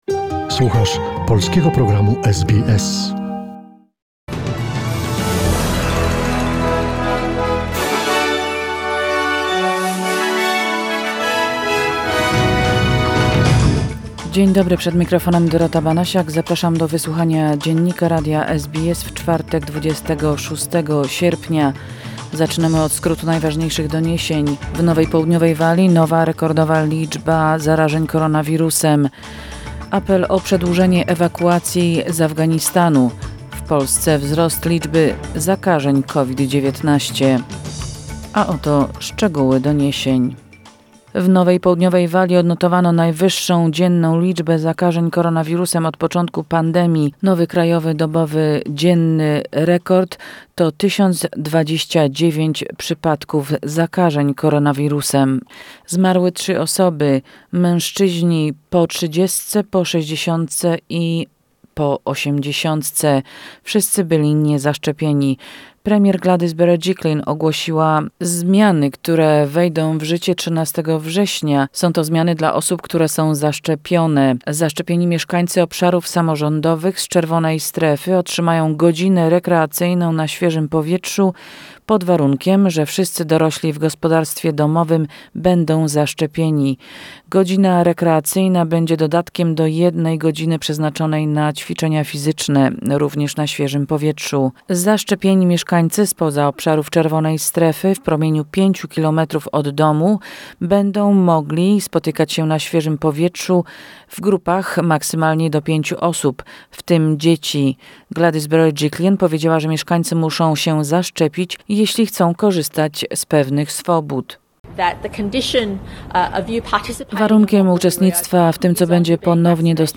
SBS News Flash in Polish, 26 August 2021